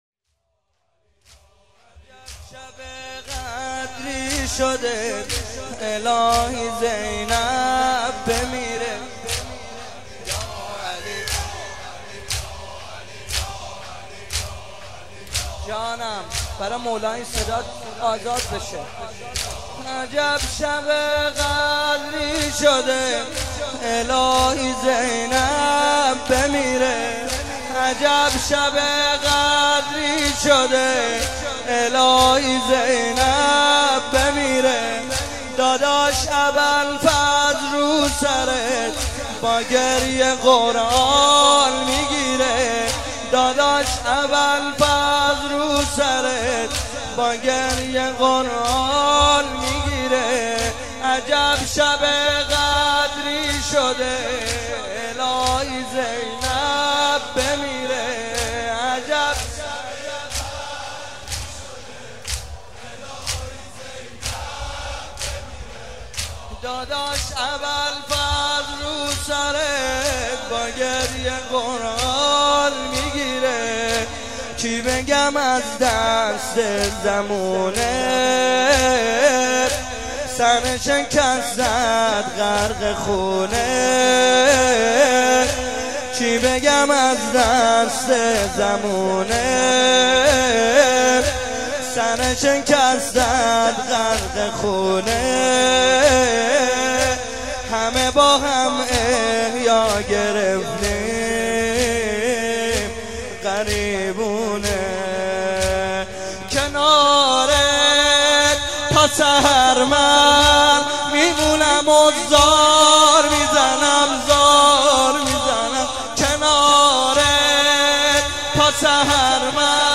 03.sineh zani.mp3